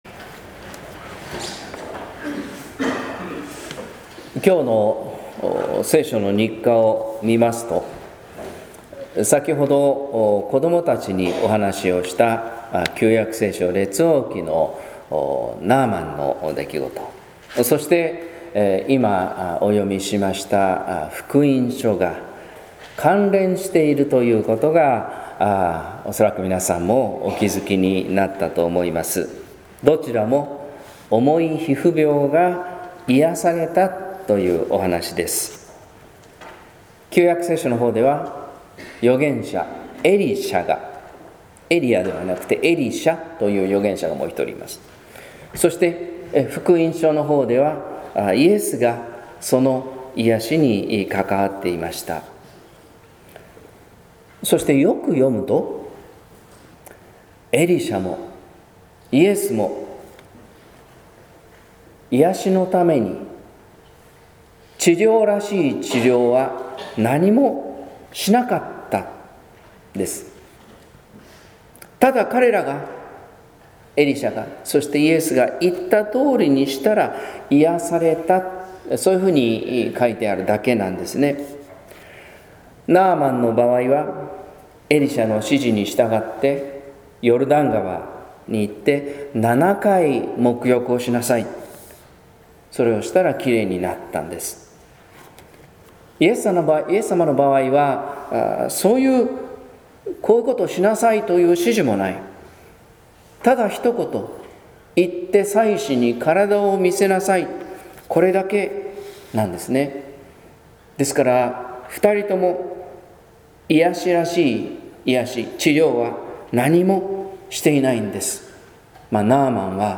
説教「あなたを救う三つのこと」（音声版）